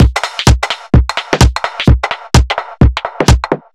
Index of /musicradar/uk-garage-samples/128bpm Lines n Loops/Beats
GA_BeatFiltC128-02.wav